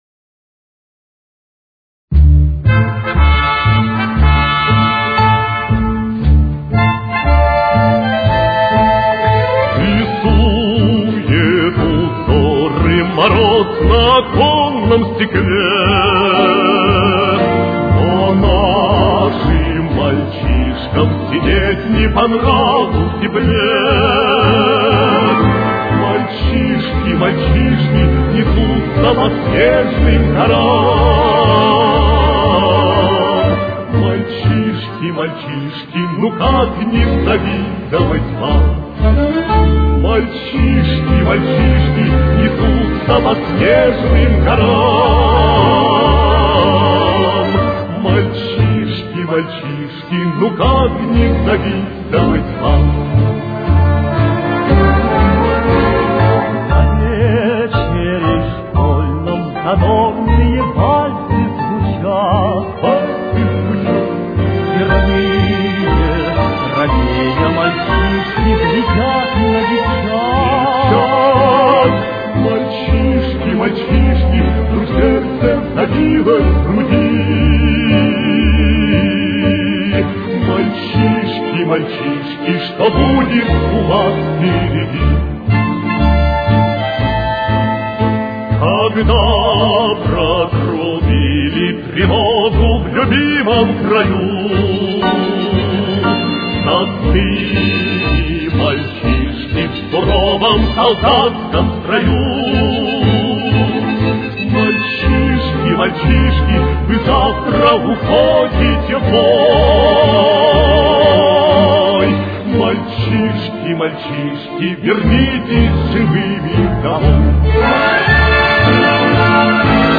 Темп: 122.